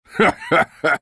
vs_fSrArtus_haha.wav